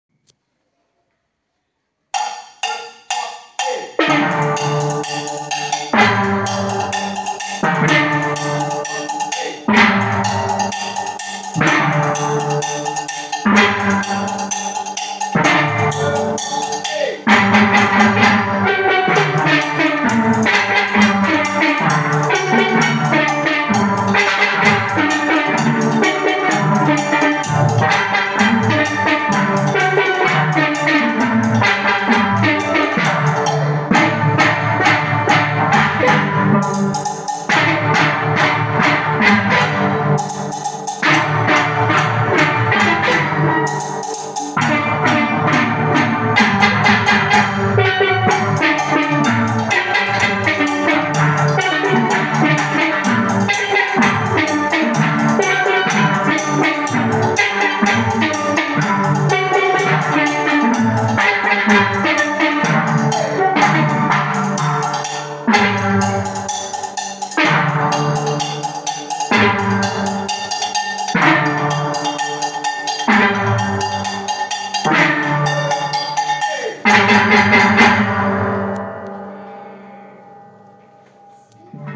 La classe orchestre joue tous les mardis et jeudis avec deux musiciens de l'école de musique d'Alès avec des steeldrums.